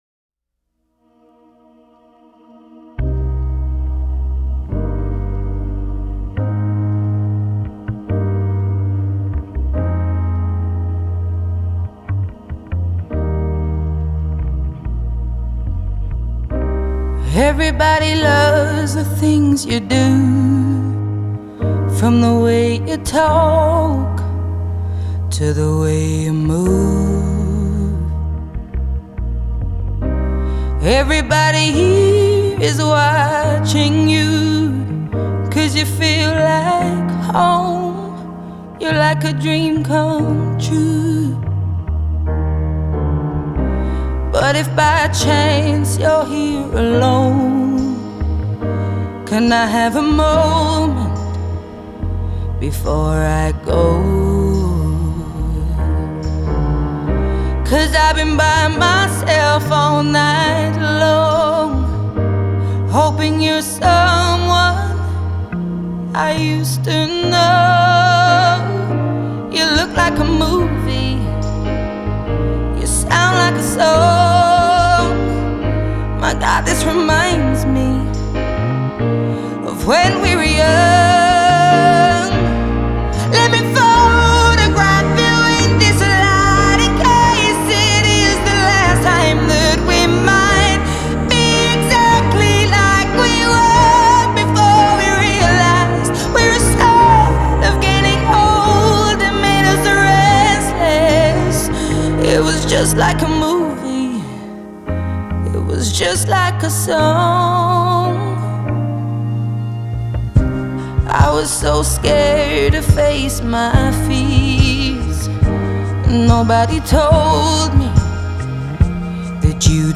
Genre: Pop,Blues